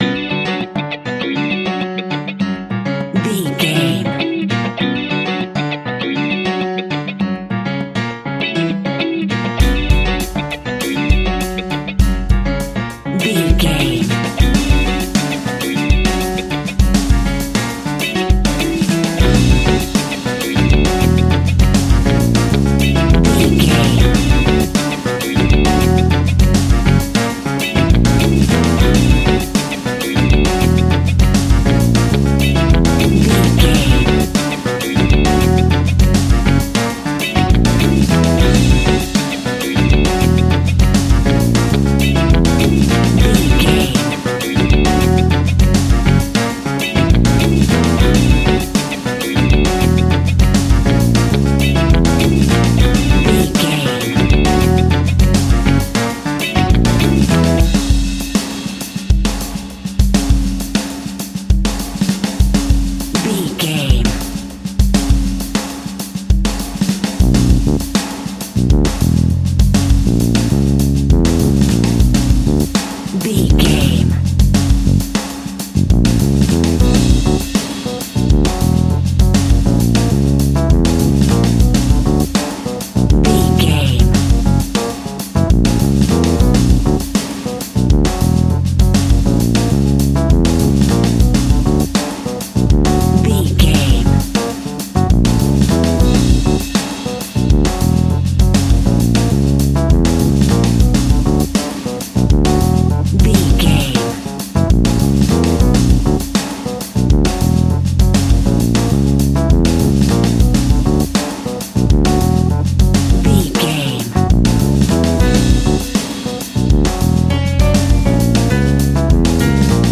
Aeolian/Minor
latin
salsa
drums
bass guitar
percussion
brass
saxophone
trumpet
fender rhodes
clavinet